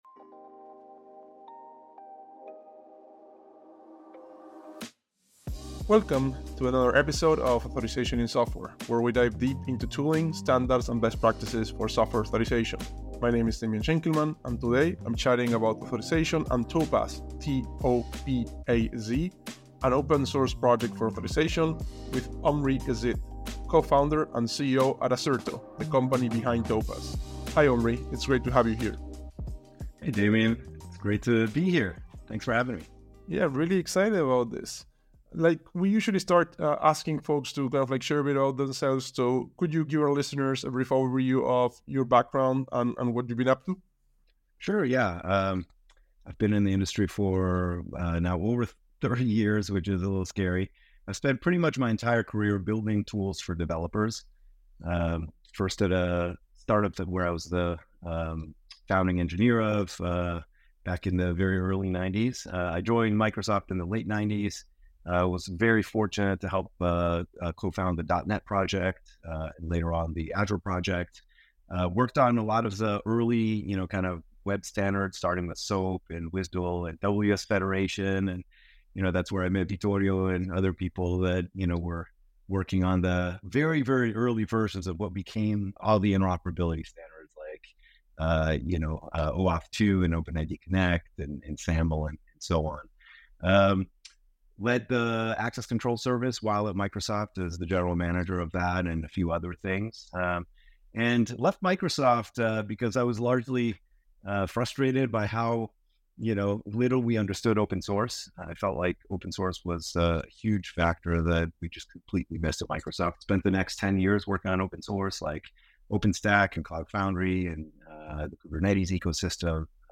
Play Rate Listened List Bookmark Get this podcast via API From The Podcast Authorization in Software features chats with industry subject matter experts in Authorization.